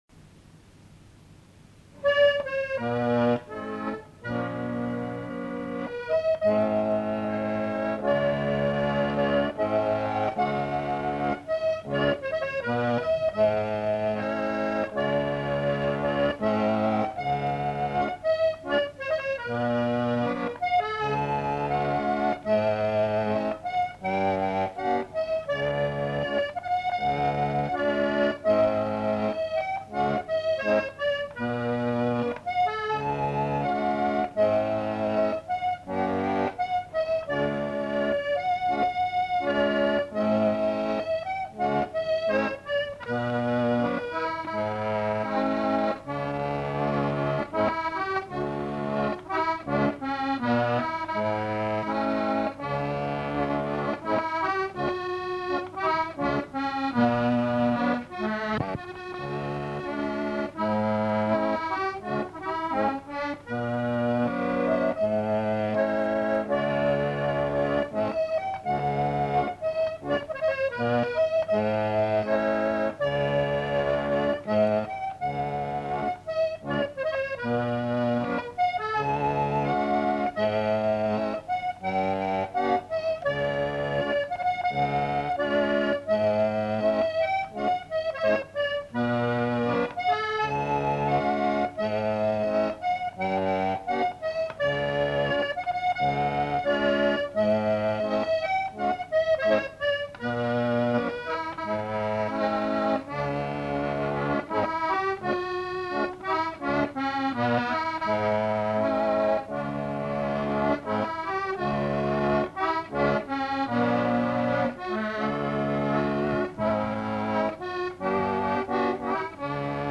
Instrumental. Accordéon diatonique. Bretagne
Aire culturelle : Bretagne
Genre : morceau instrumental
Instrument de musique : accordéon diatonique